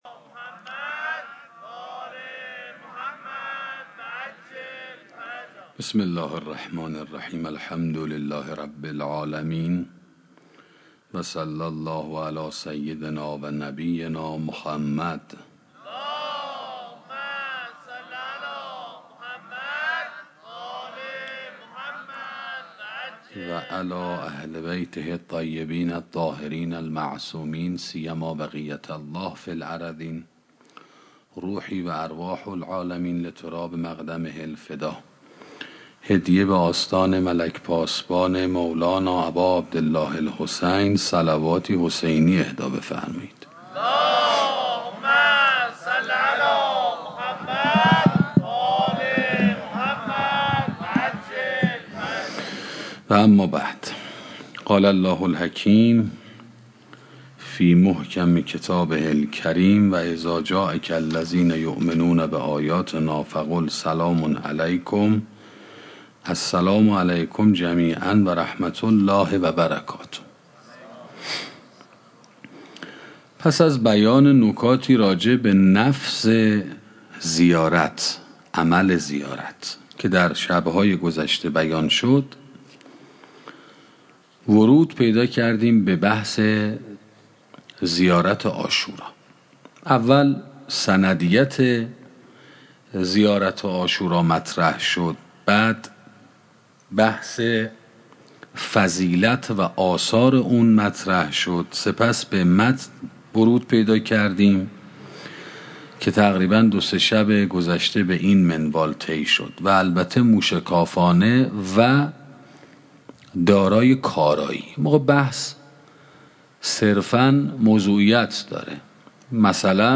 سخنرانی شرح زیارت عاشورا 13 - موسسه مودت